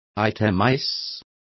Also find out how detalle is pronounced correctly.